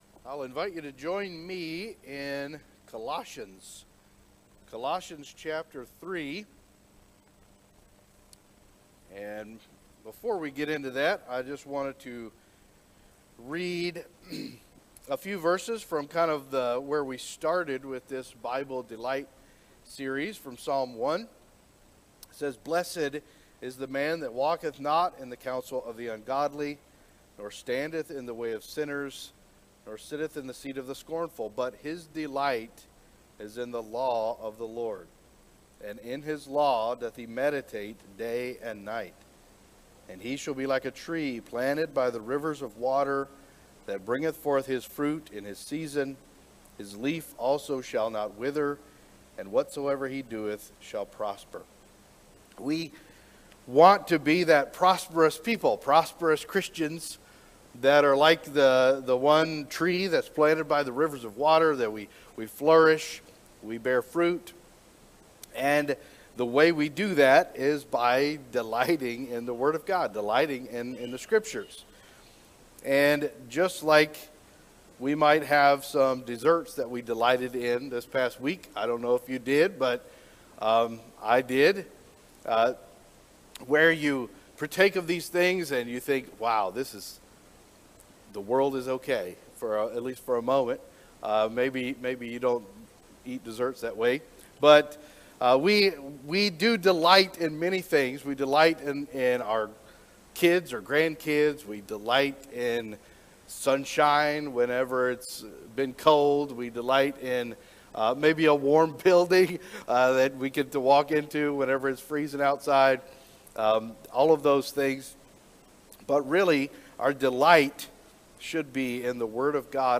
Our latest Sunday morning worship service Messages from God’s word
Colossians 3:16 Share this sermon